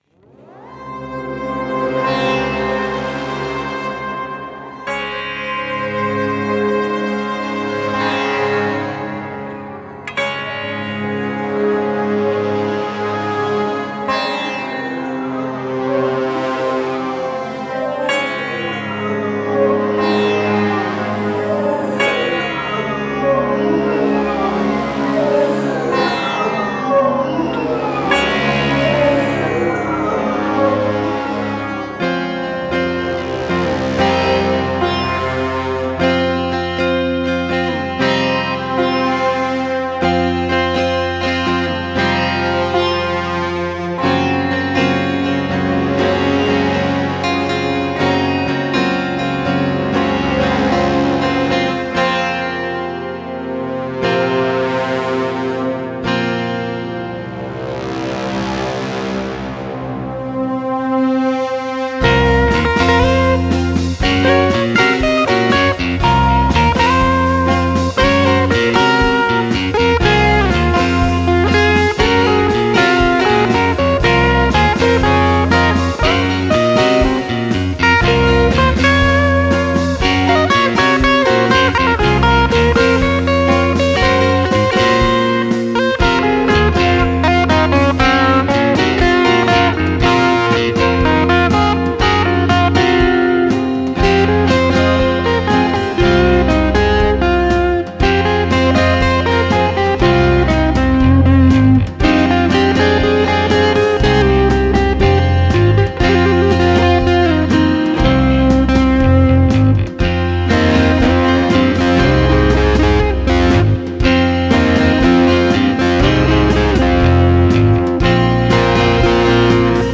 Alle Stücke auf dieser Seite liegen in CD-Qualität vor.